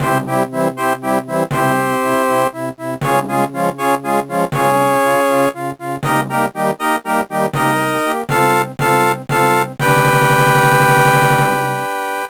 The slower arrangement